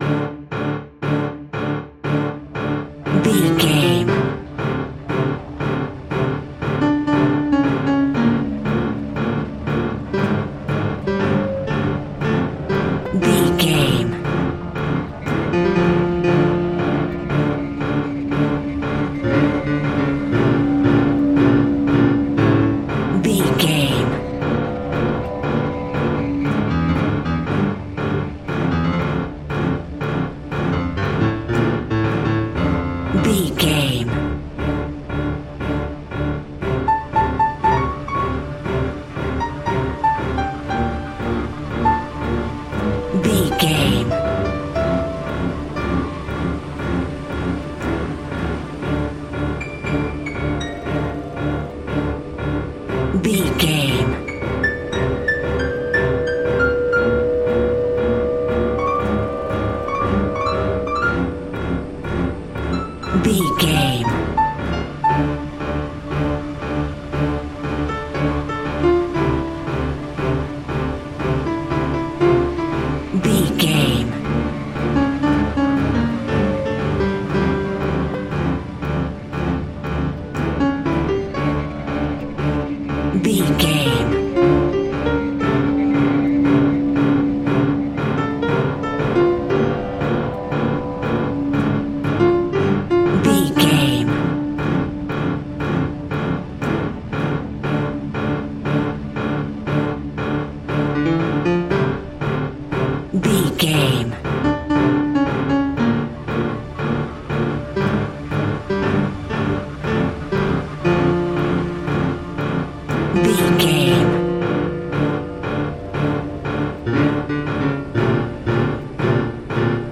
In-crescendo
Thriller
Aeolian/Minor
Fast
ominous
suspense
eerie
strings
synth
ambience
pads